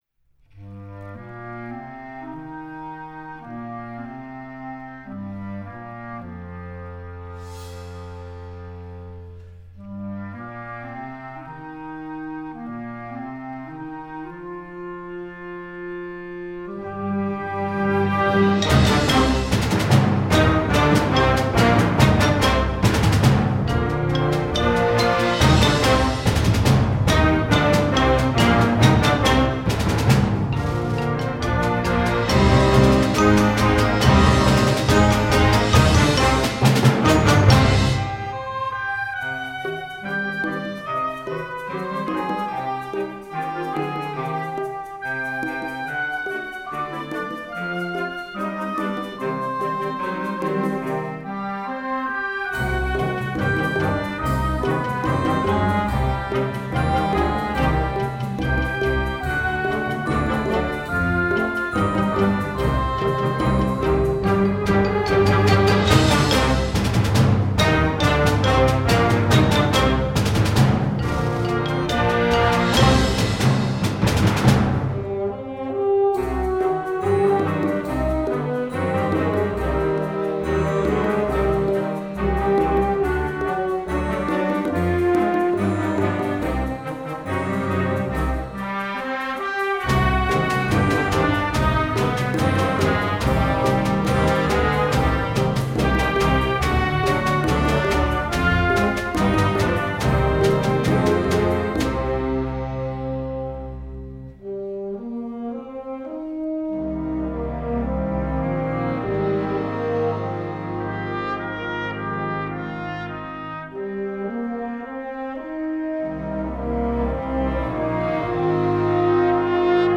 Gattung: Konzertwerk für flexibles Jugendblasorchester
Besetzung: Blasorchester